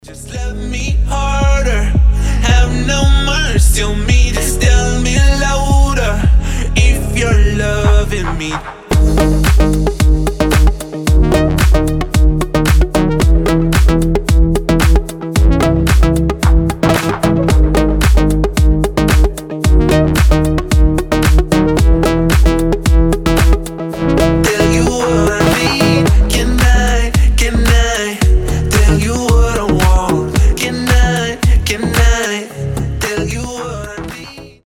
• Качество: 320, Stereo
deep house
nu disco
Indie Dance